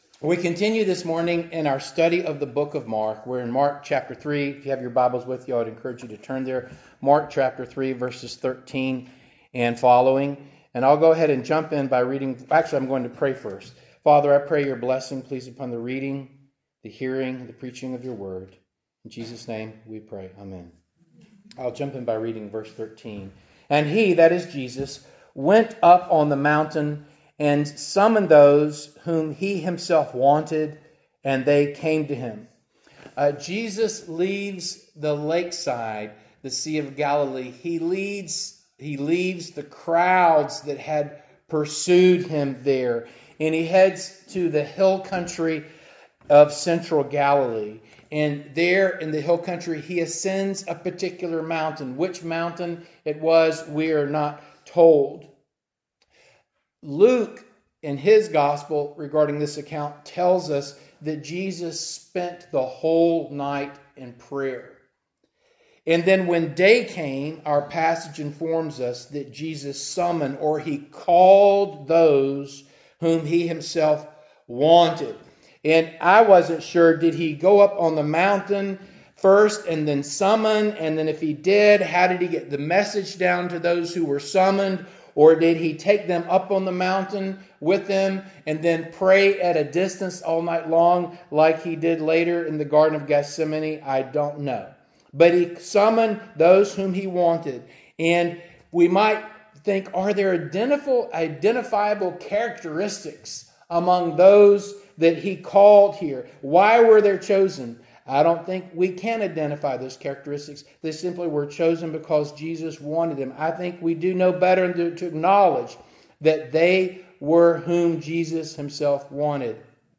Passage: Mark 3:13-19 Service Type: Morning Service